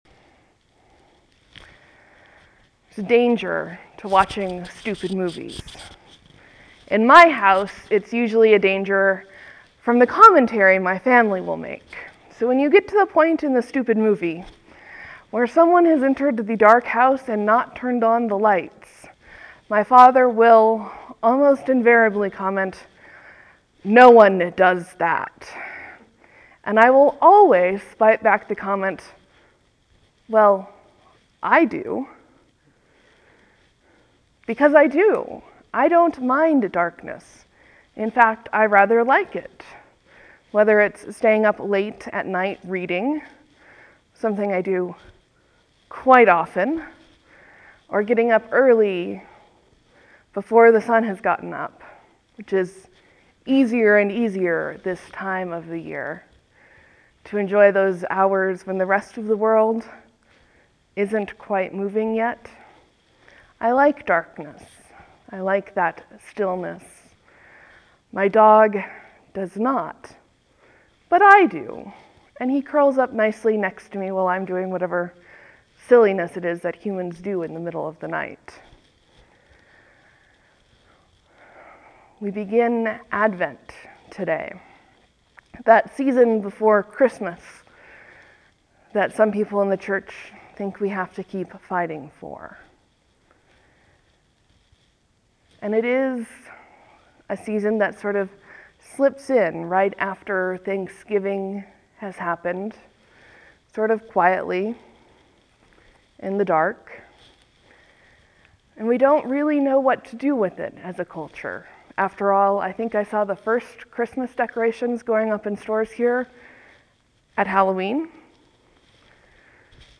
Advent, Sermon, Leave a comment
(There will be a few moments of silence before the sermon begins. Thank you for your patience.)